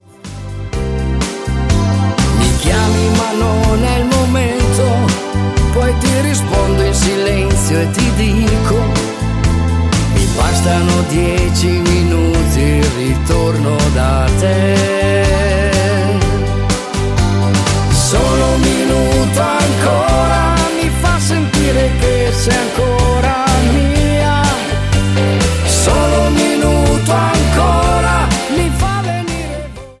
MODERATO  (03,49)